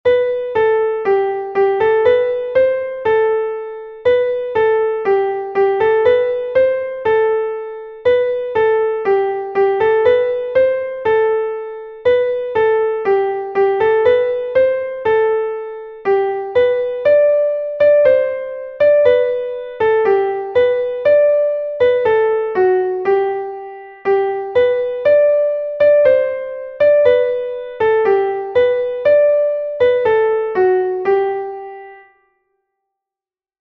An dro